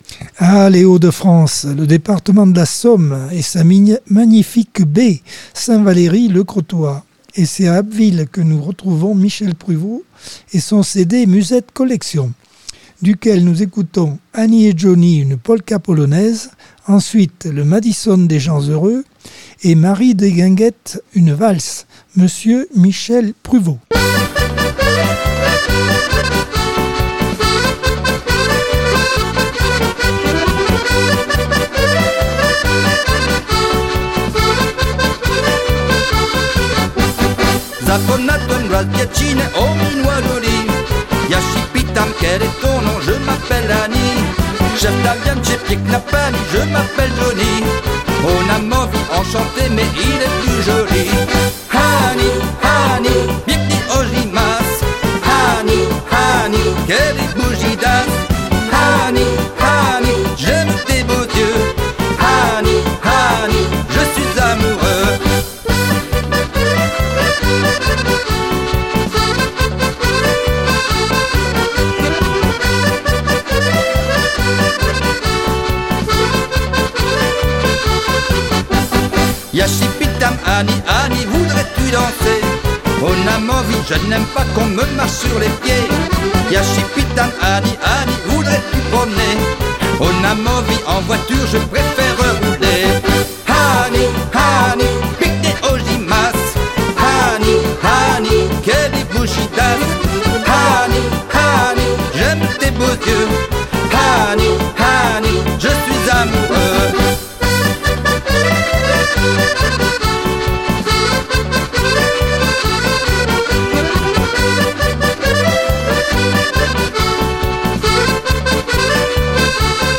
Accordeon 2024 sem 35 bloc 2 - Radio ACX